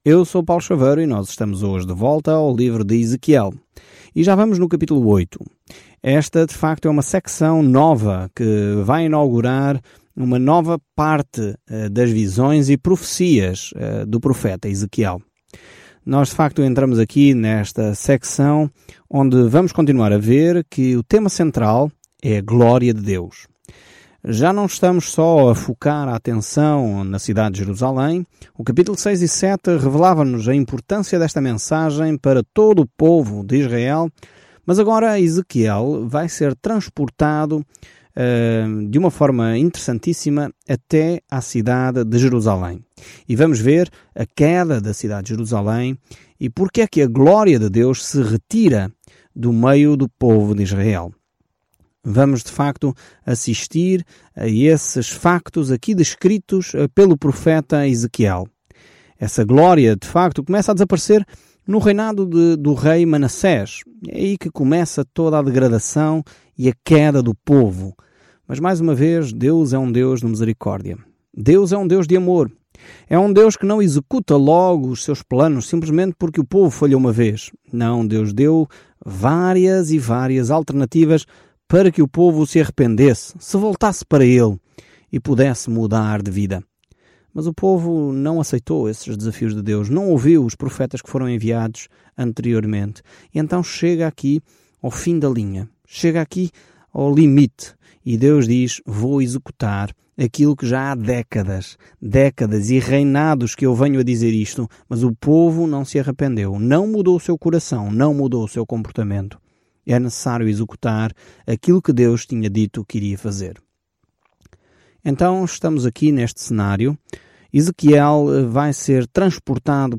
Scripture Ezekiel 8 Ezekiel 9 Day 6 Start this Plan Day 8 About this Plan O povo não quis ouvir as palavras de advertência de Ezequiel para retornar a Deus, então, em vez disso, ele encenou as parábolas apocalípticas, e isso perfurou o coração das pessoas. Viaje diariamente por Ezequiel enquanto ouve o estudo em áudio e lê versículos selecionados da palavra de Deus.